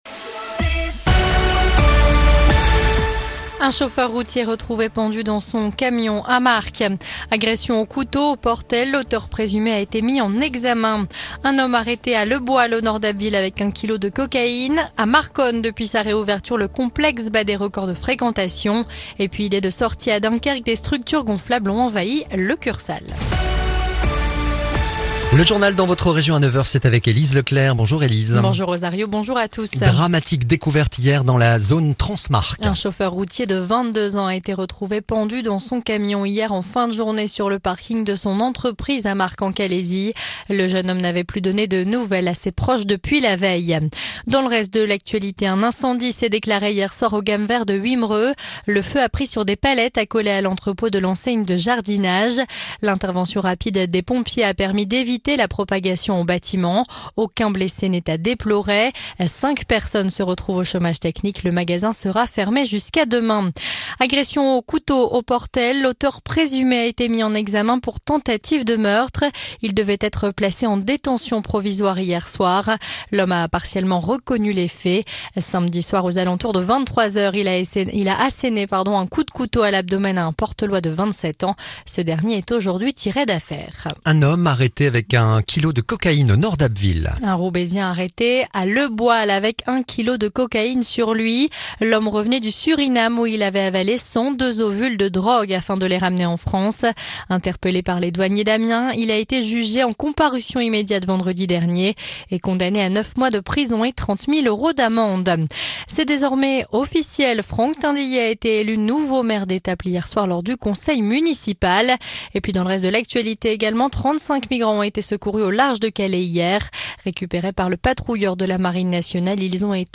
Le journal du mardi 2 août